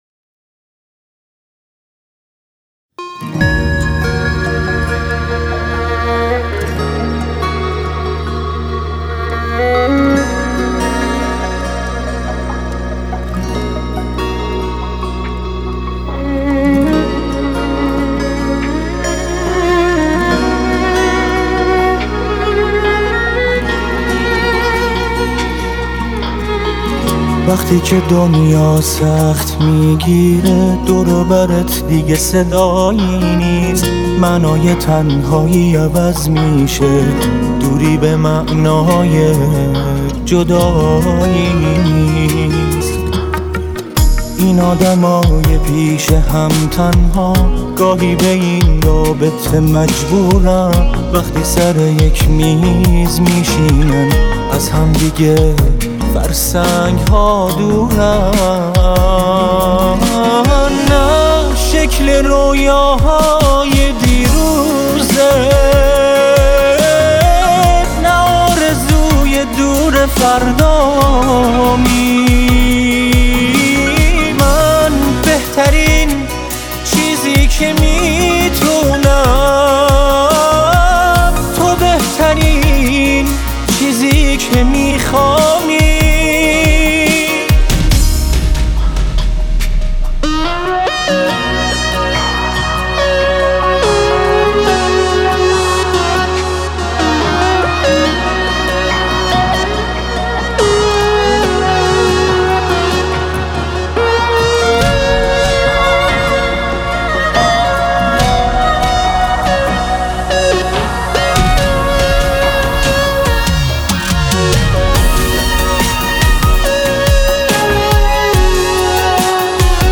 خواننده جوان عرصه پاپ
گیتار
ویلون